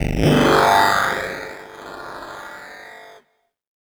Session 08 - Bit FX.wav